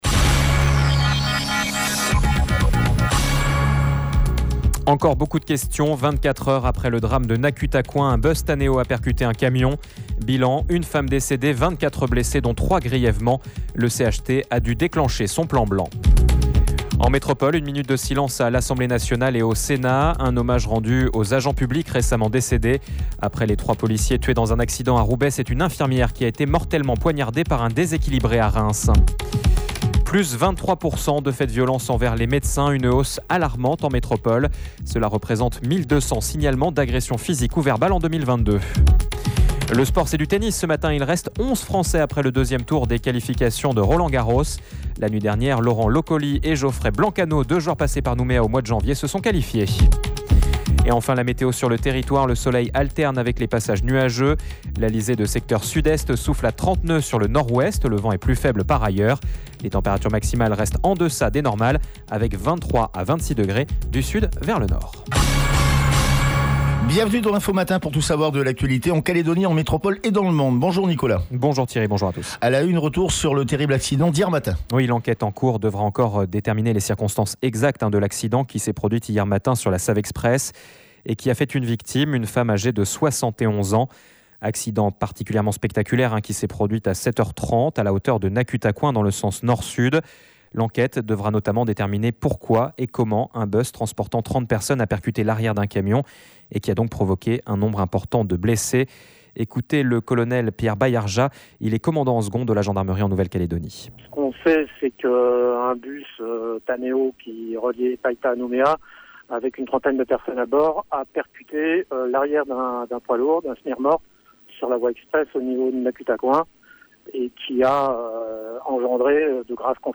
JOURNAL : MERCREDI INFO MATIN